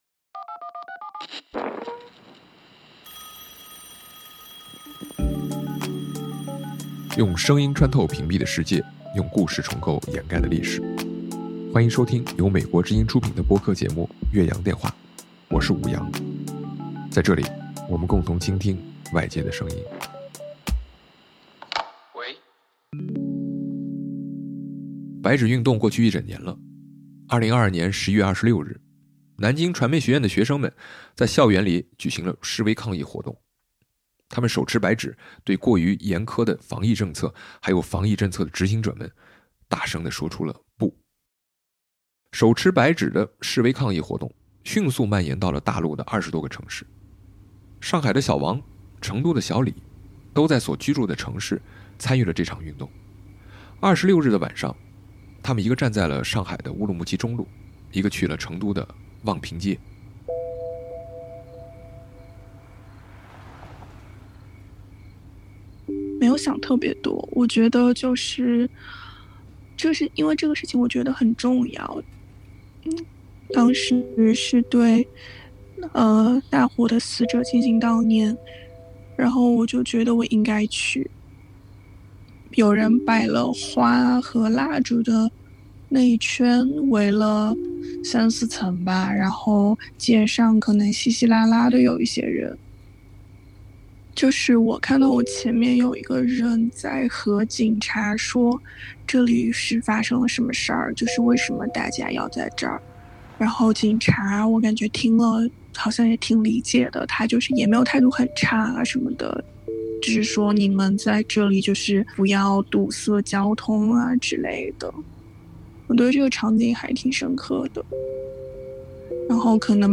我们找到了国内和海外的五位参与者，让TA们讲讲参与白纸，以及这一年里发生的故事。